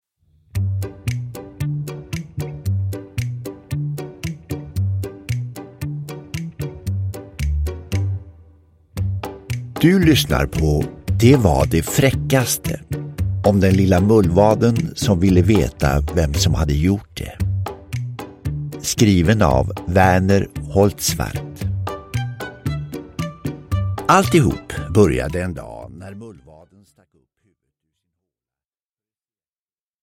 Den klassiska bilderboken om mullvaden och det mystiska bajset, nu i härlig inläsning av Claes Månsson och med många roliga ljudeffekter.
Uppläsare: Claes Månsson